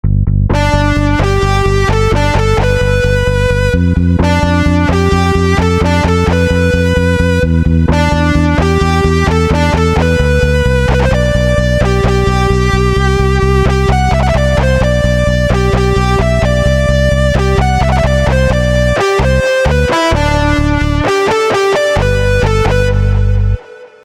베이스 리프까지 따시다니..